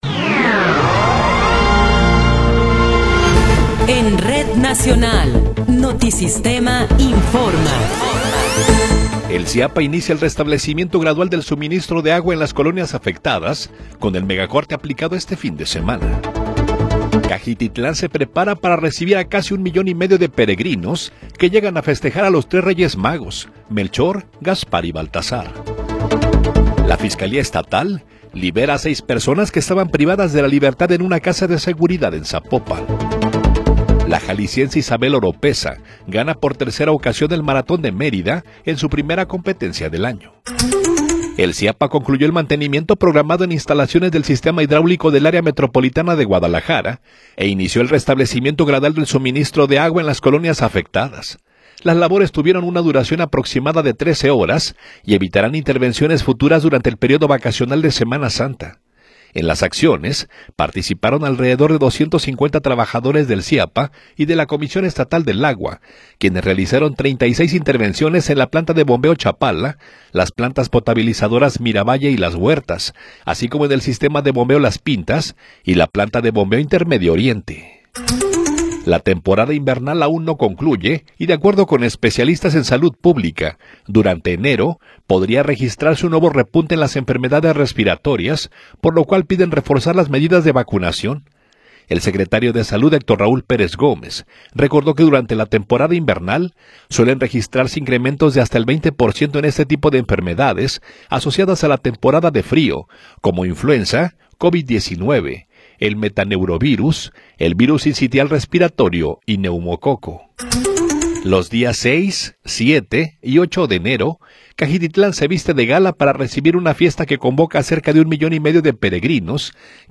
Noticiero 9 hrs. – 5 de Enero de 2026
Resumen informativo Notisistema, la mejor y más completa información cada hora en la hora.